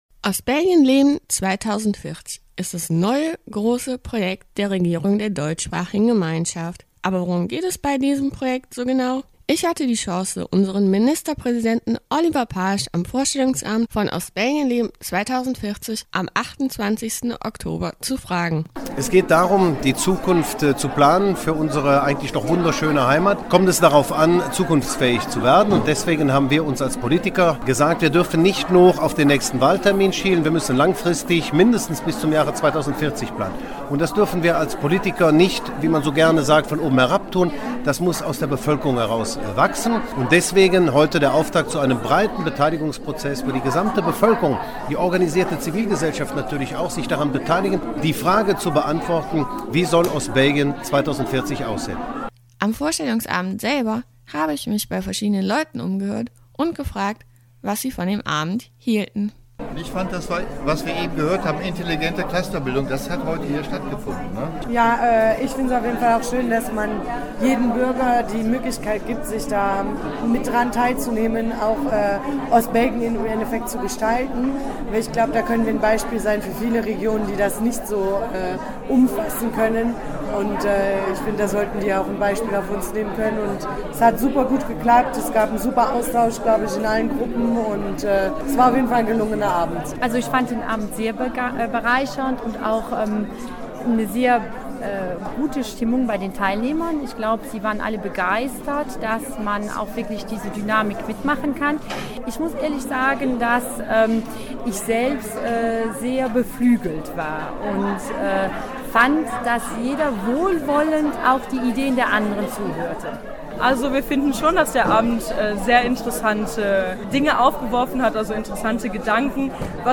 Ostbelgien Leben 2040 – Die Reportage – Radio Contact – Ostbelgien NOW